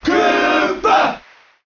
File:Bowser Cheer Japanese SSBM.ogg
Bowser_Cheer_Japanese_SSBM.ogg